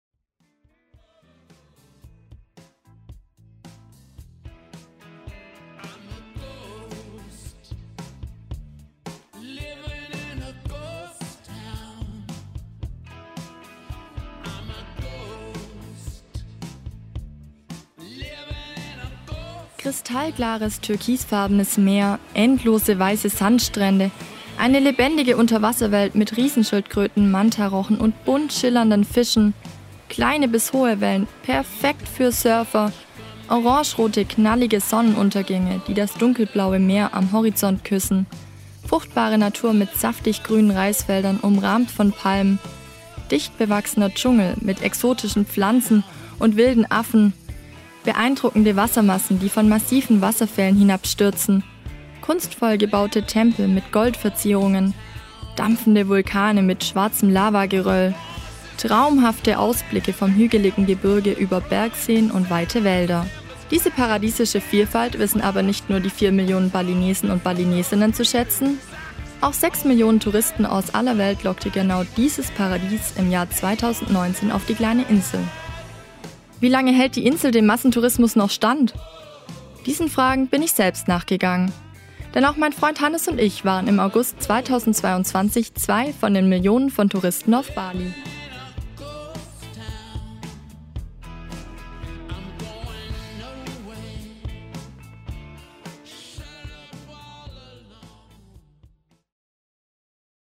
Teil 1 eines Reiseberichts.